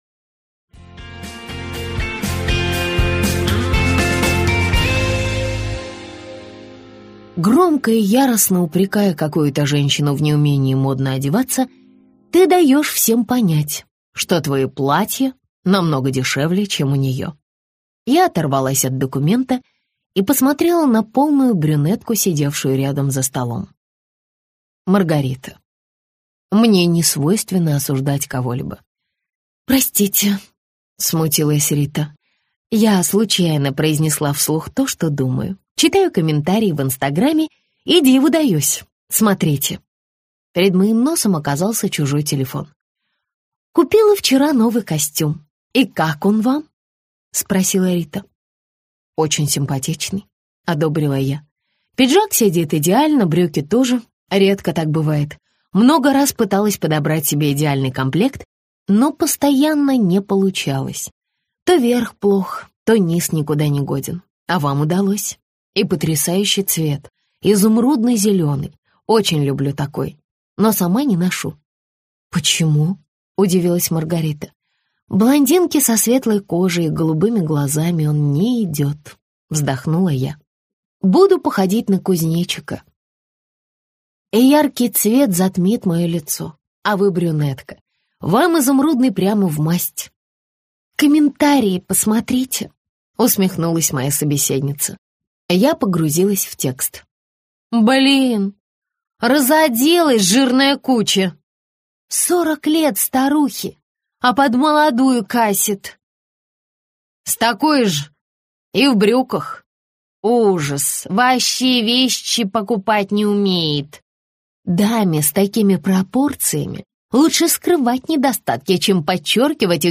Аудиокнига Вечный двигатель маразма - купить, скачать и слушать онлайн | КнигоПоиск